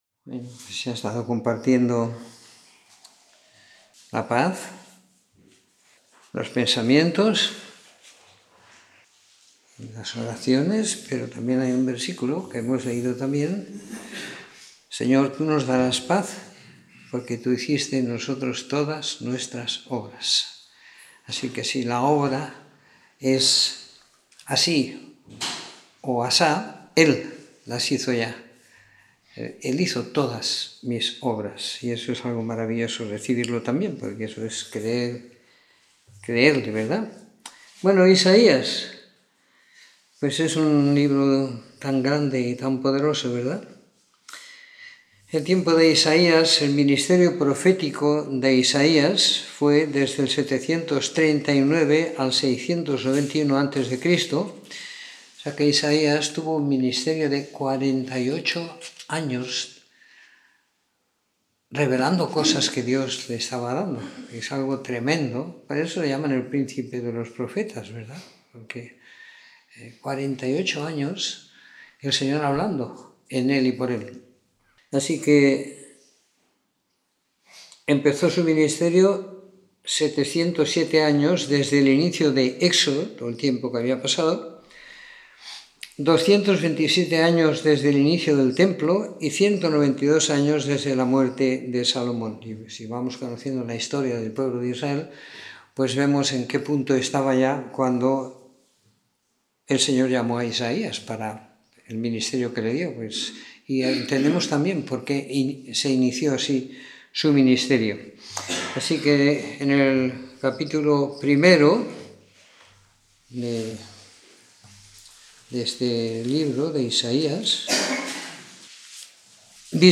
Comentario en el libro de Isaías siguiendo la lectura programada para cada semana del año que tenemos en la congregación en Sant Pere de Ribes.